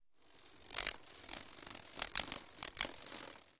Шум взмахов крыльев колибри